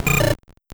Cri de Héricendre dans Pokémon Or et Argent.